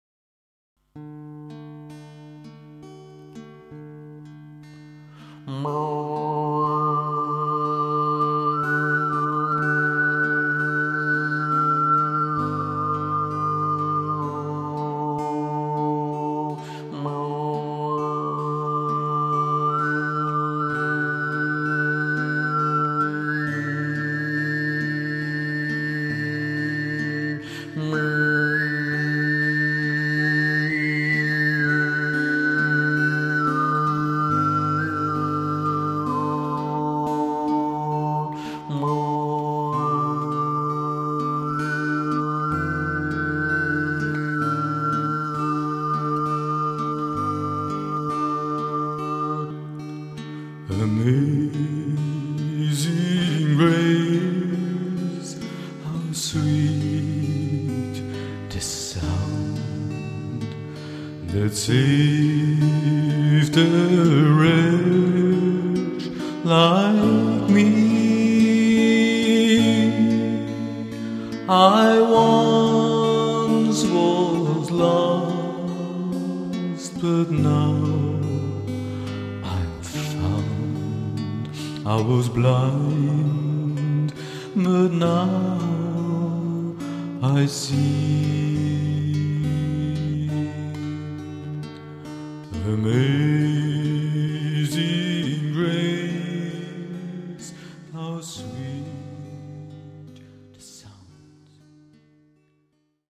Eine Sammlung von bekannten Gospels & Spirituals im noch recht unbekannten Stil des Obertongesanges.
Von meinem Gitarrespiel begleitet, singe ich abwechselnd im Grundton und Oberton.
Obertongesang klingt ähnlich einer zarten weichen Flöte und ist dennoch viel erhabener und leichter.